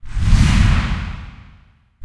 PixelPerfectionCE/assets/minecraft/sounds/mob/enderdragon/wings3.ogg at mc116
wings3.ogg